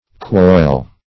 quoil - definition of quoil - synonyms, pronunciation, spelling from Free Dictionary
quoil - definition of quoil - synonyms, pronunciation, spelling from Free Dictionary Search Result for " quoil" : The Collaborative International Dictionary of English v.0.48: Quoil \Quoil\ (kwoil or koil), n. See Coil .